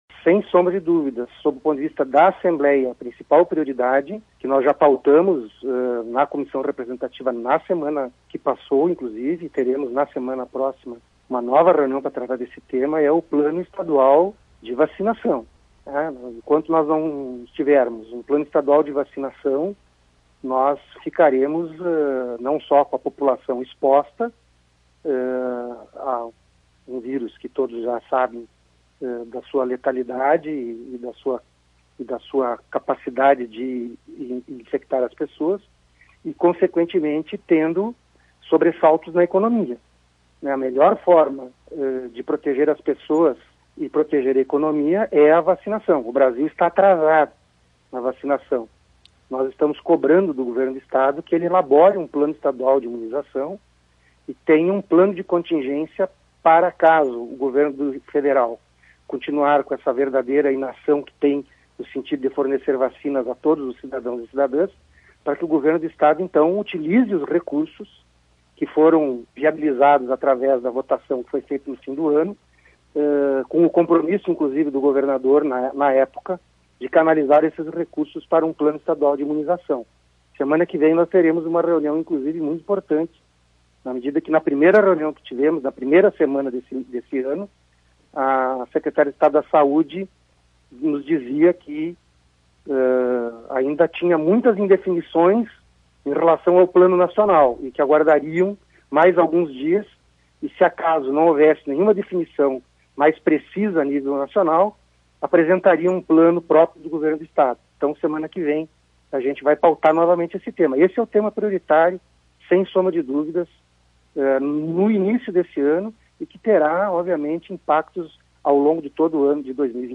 Em entrevista à Tua Rádio São Francisco, Pepe Vargas conta que a Comissão de Saúde do órgão se reuniu com a secretária estadual de Saúde (SES), Arita Bergmann, para conversar sobre a organização do plano.